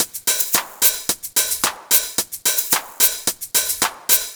110_HH+shaker_1.wav